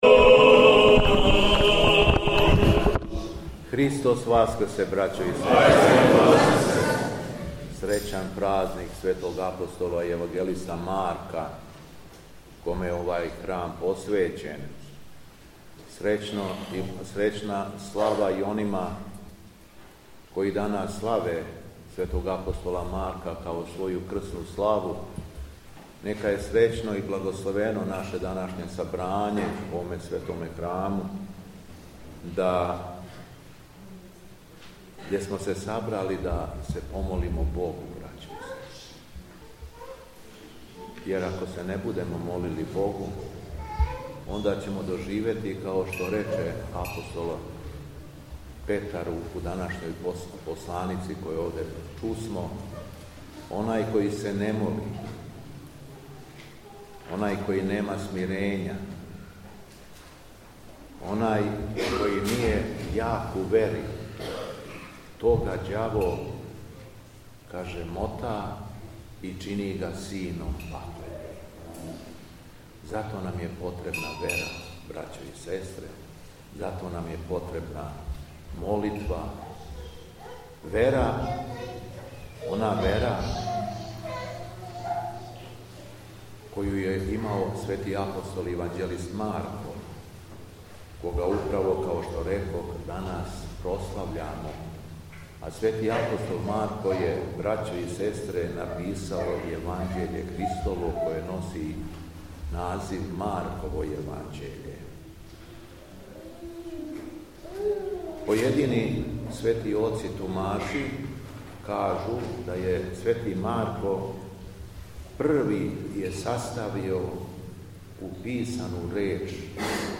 СВЕТА АРХИЈЕРЕЈСКА ЛИТУРГИЈА У ВУЧИЋУ ПОВОДОМ СЛАВЕ ЦРКВЕ СВЕТОГ АПОСТОЛА И ЈЕВАНЂЕЛИСТА МАРКА - Епархија Шумадијска
Беседа Његовог Високопреосвештенства Митрополита шумадијског г. Јована
По прочитаном јеванђелском зачалу Високопреосвећени митрополит је упутио следеће речи верном народу: